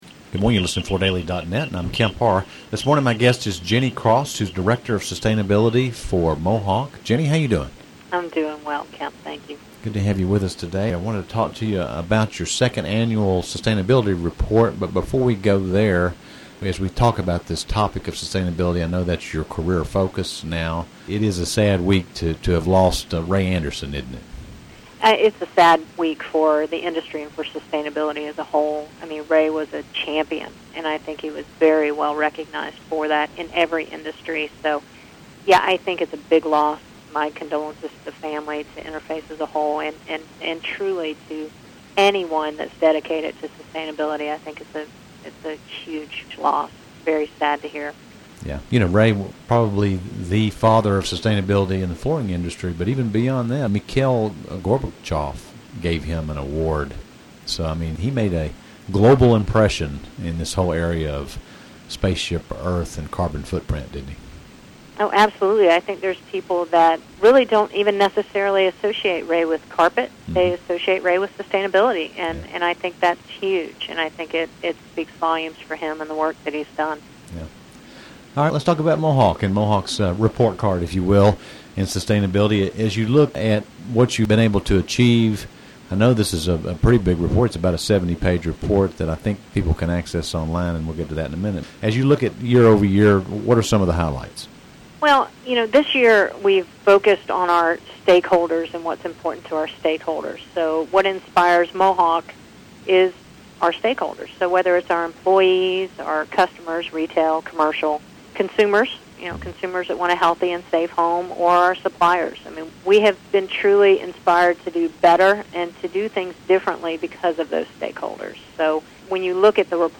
Listen to the interview to hear more details about Mohawk's third party validation with this report.